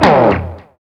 Index of /90_sSampleCDs/Roland L-CDX-01/GTR_GTR FX/GTR_Gtr Hits 1
GTR GTR DOWN.wav